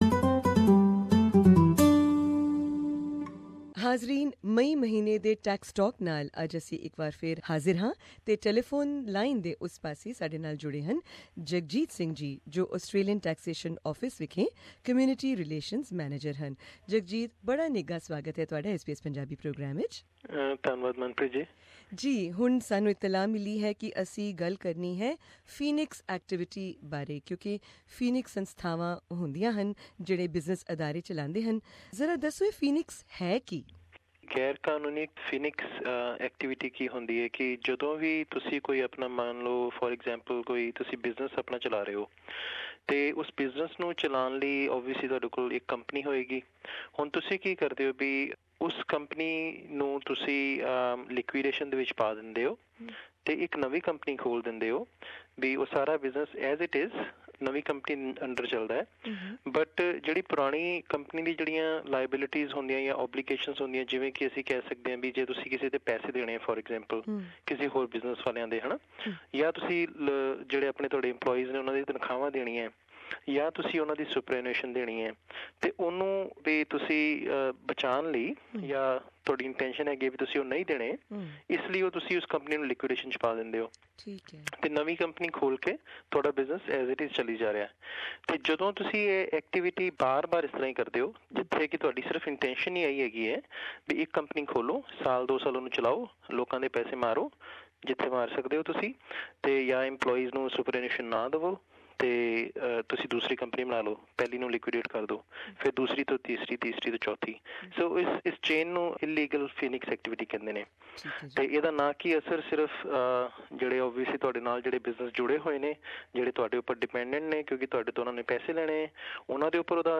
The following community information is brought to you by the Australian Taxation Office. Interview